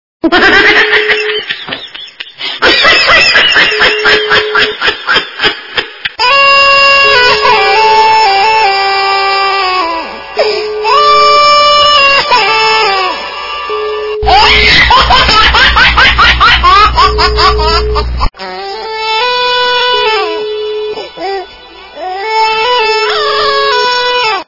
» Звуки » Смешные » Сумашедший - смех и плач
При прослушивании Сумашедший - смех и плач качество понижено и присутствуют гудки.
Звук Сумашедший - смех и плач